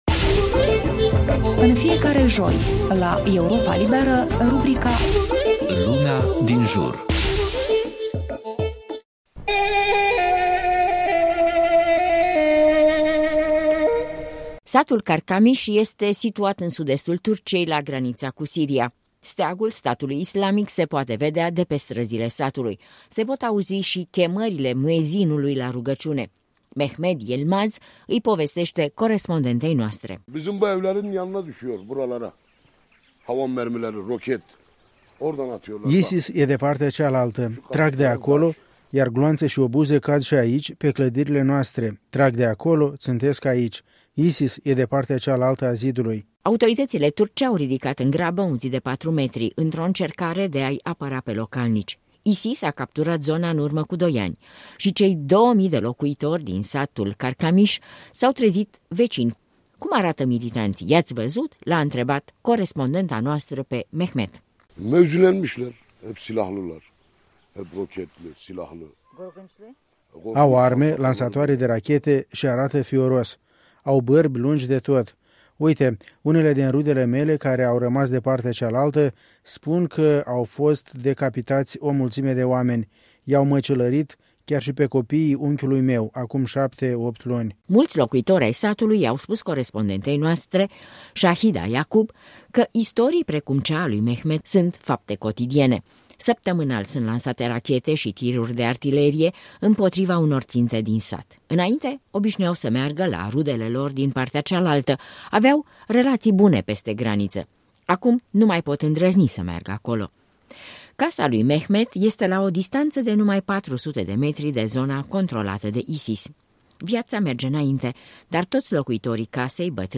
Se pot auzi si chemările muezinului la rugăciune.